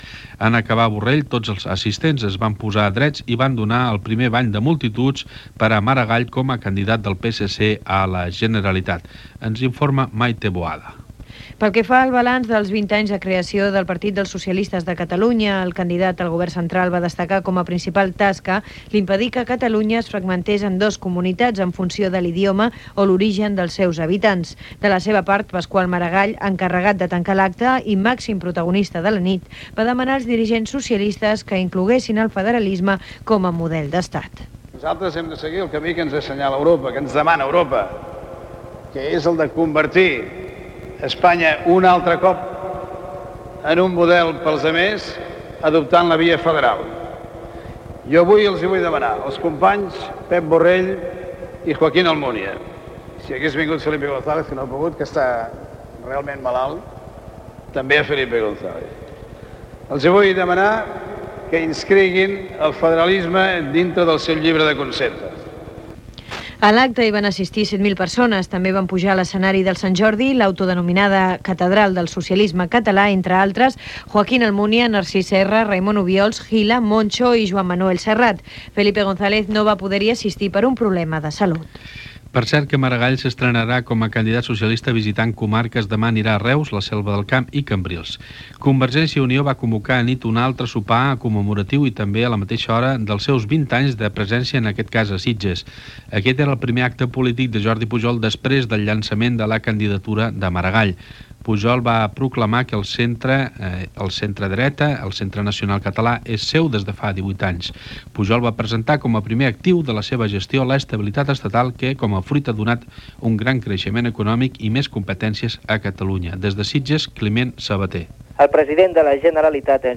Pasqual Maragall, candidat a president de la Generalitat pel PSC; acte dels 20 anys de CiU amb Jordi Pujol a Sitges; Unió de Pagesos. Careta de sortida, promo "Premis Joaquim Serra" i indicatiu de l'emissora.
Informatiu